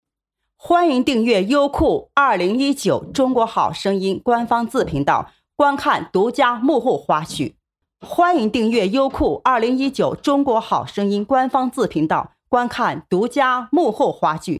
女国145_动画_模仿_模仿那英音频.mp3